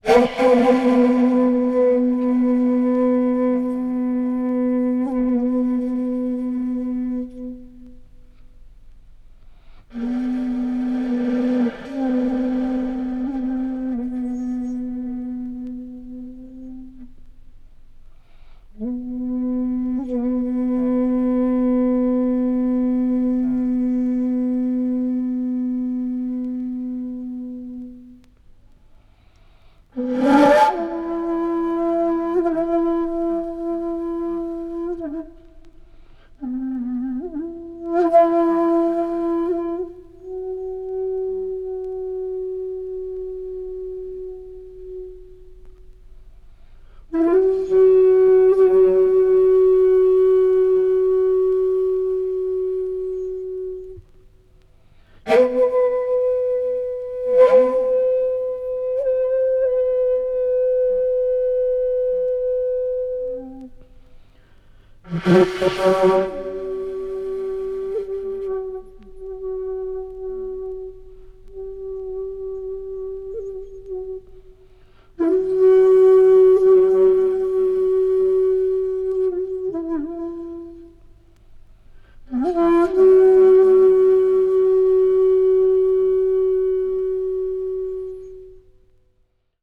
media : EX/EX(わずかにチリノイズが入る箇所あり)
アヴァンギャルド・ピース。